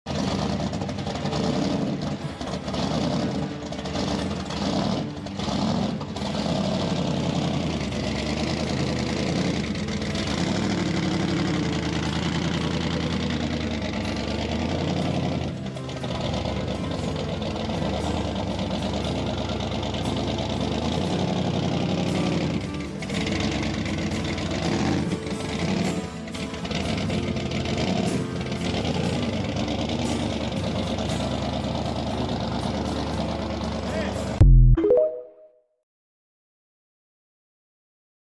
No need for music on this bad Blown 57 Chevy.